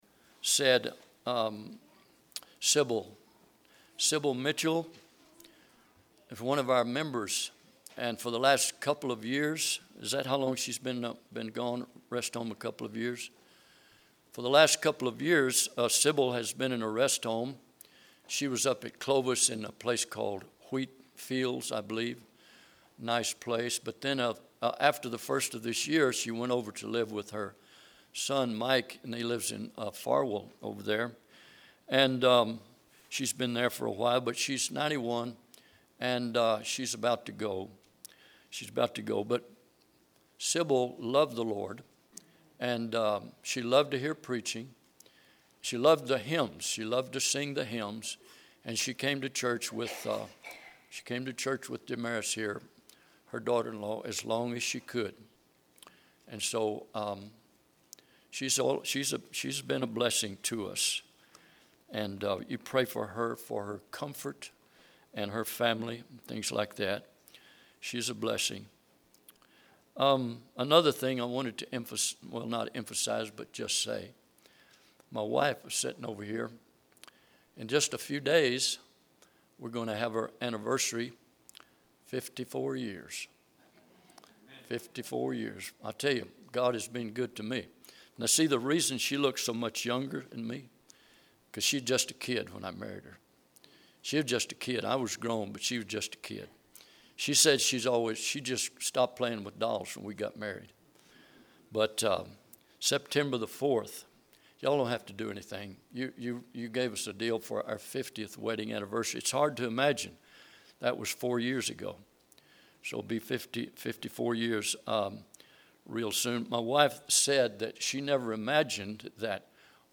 Ephesians 6:10-18 Service Type: Sunday am Bible Text